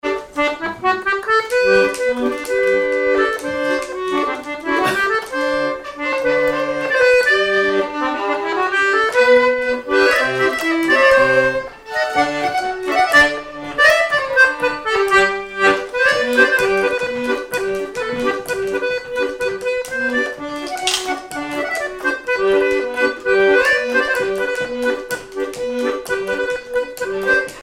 Miquelon-Langlade
danse : marche
violon
Pièce musicale inédite